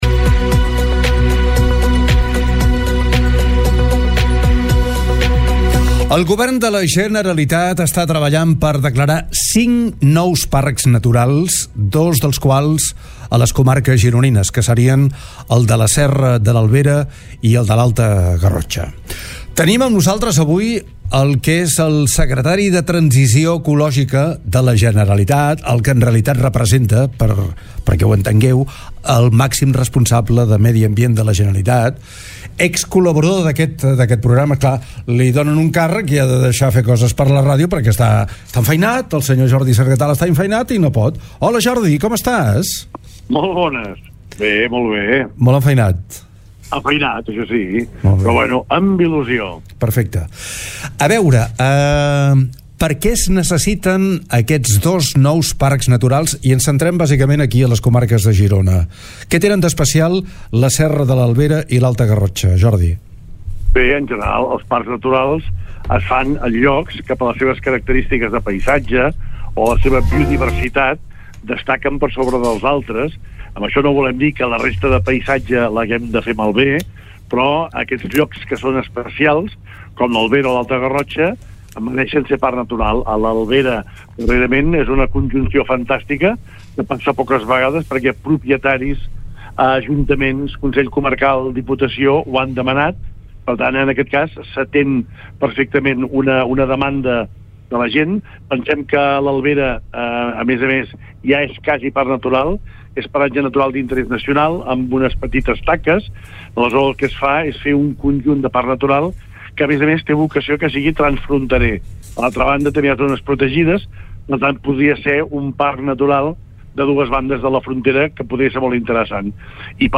La Generalitat vol crear dos nous parcs naturals a la demarcació de Girona. Jordi Sargatal, secretari de Transició Ecològica, creu que podrien ser una realitat abans d’acabar aquesta legislatura, d’aquí a 4 anys. Ho ha explicat a, TARDA CAPITAL.